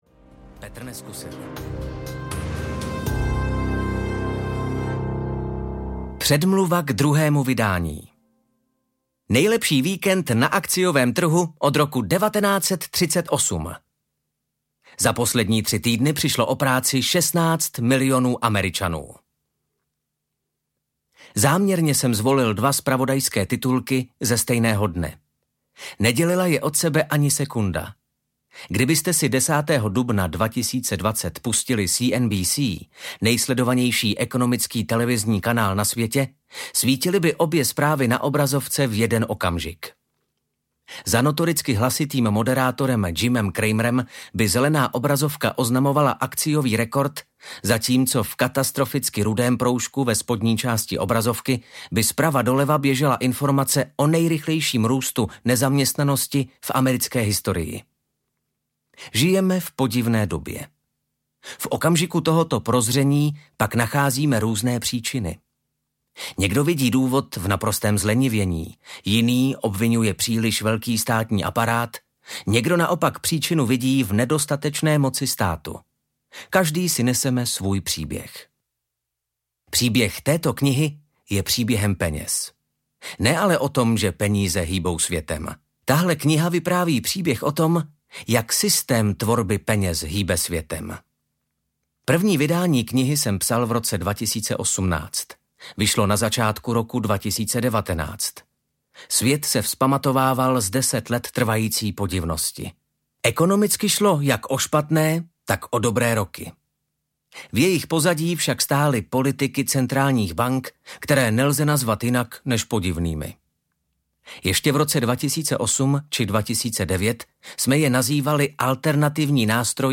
Ekonomické bubliny audiokniha
Ukázka z knihy